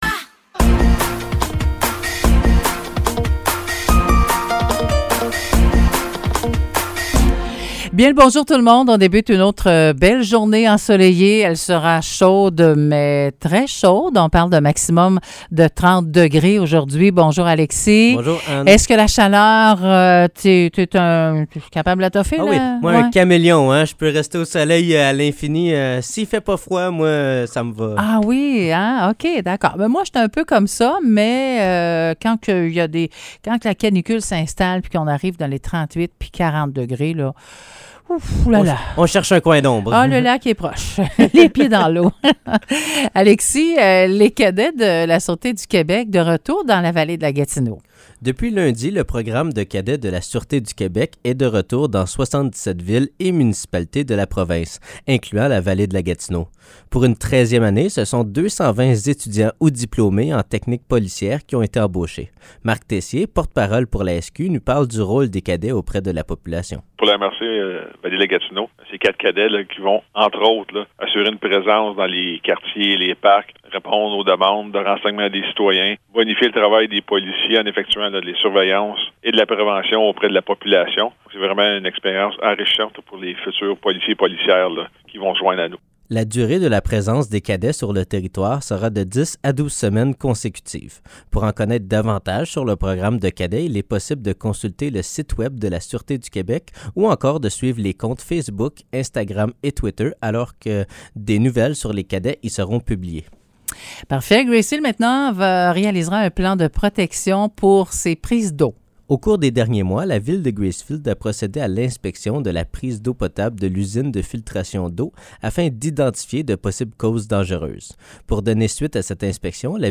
Nouvelles locales - 30 mai 2023 - 9 h